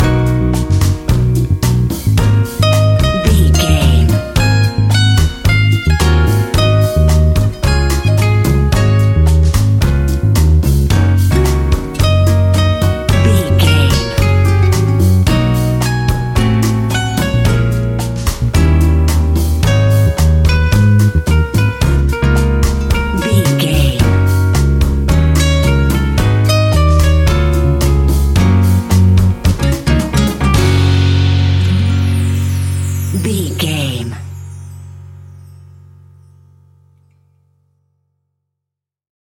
An exotic and colorful piece of Espanic and Latin music.
Ionian/Major
F#
romantic
maracas
percussion spanish guitar